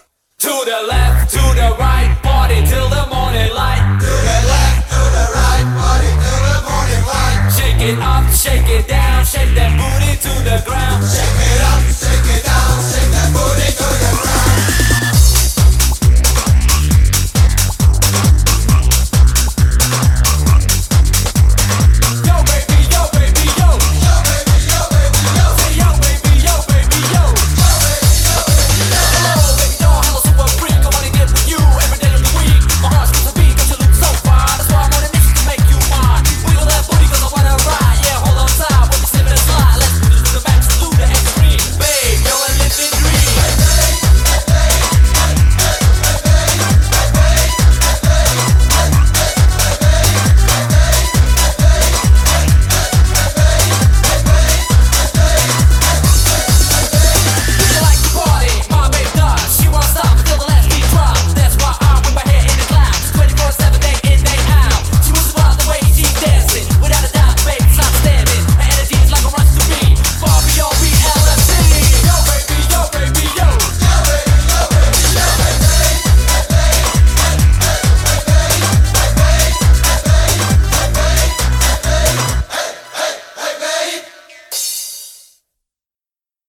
BPM134--1
- Music from custom cut